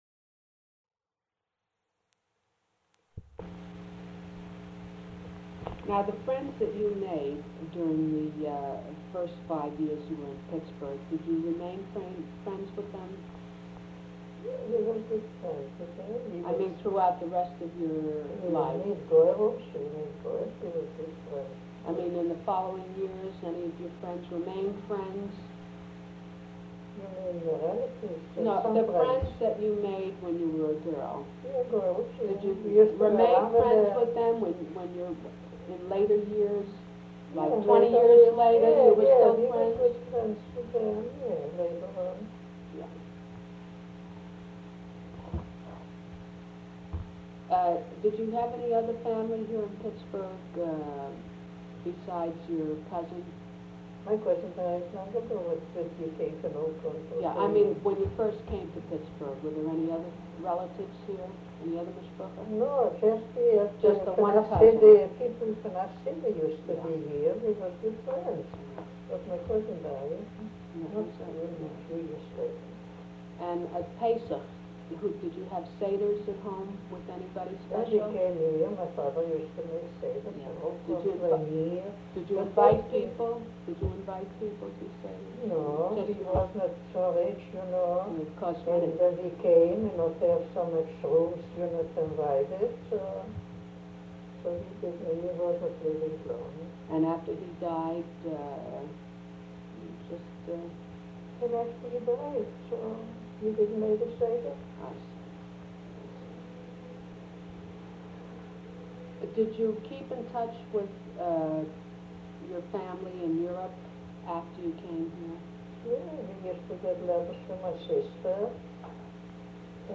TAPE QUALITY POOR